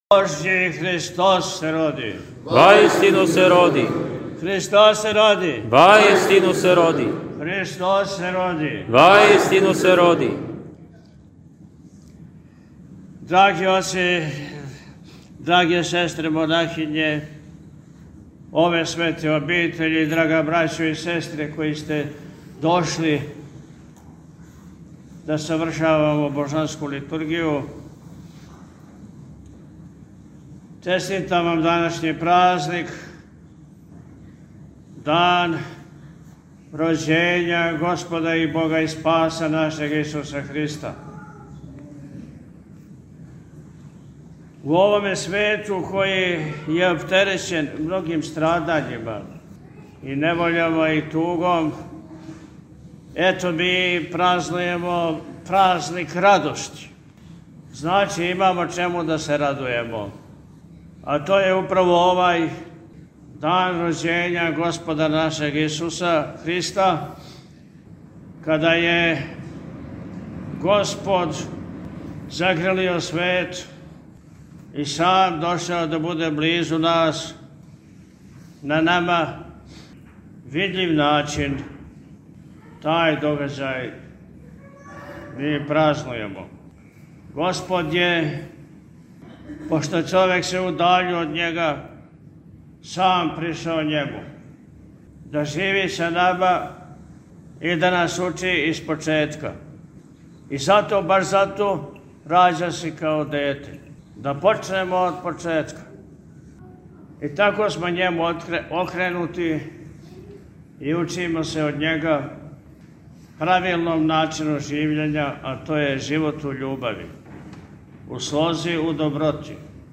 На најрадоснији хришћански празник, Рождество Христово – Божић, Његово Високопреосвештенство Архиепископ и Митрополит милешевски г. Атанасије служио је Свету архијерејску Литургију у Вазнесењском храму манастира [...]